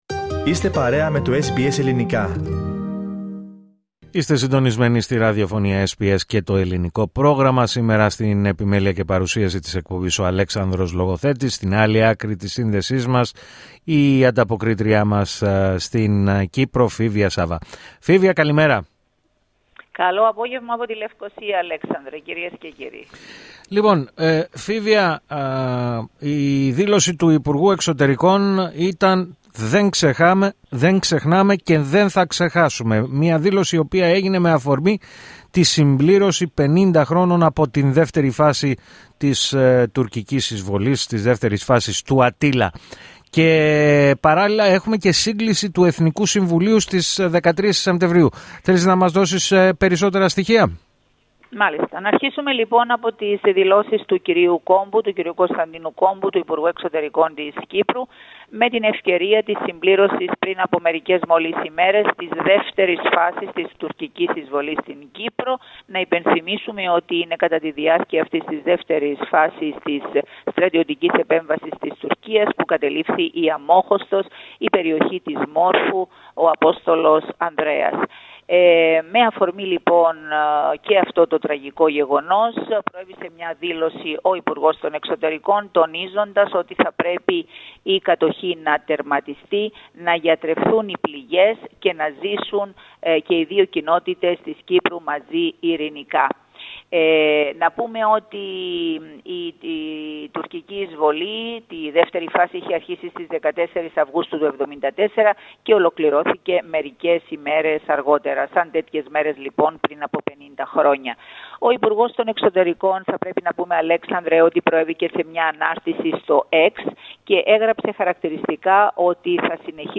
Ακούστε την εβδομαδιαία ανταπόκριση από την Κύπρο